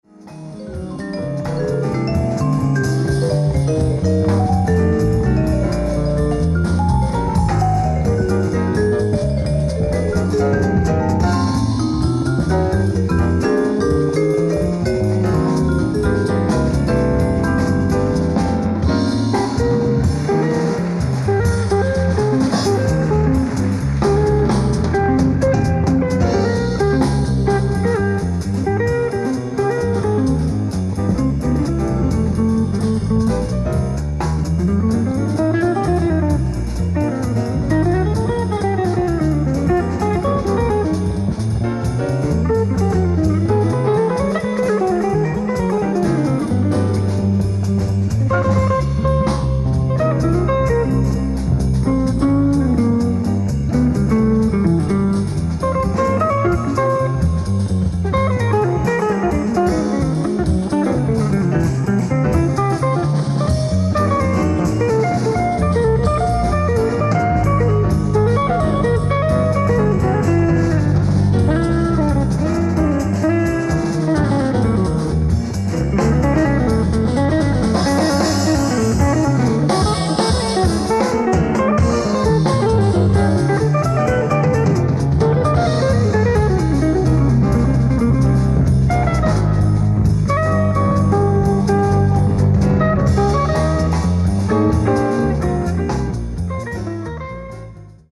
ライブ・アット・ルートヴィヒスブルク、ドイツ 11/07/1992
※試聴用に実際より音質を落としています。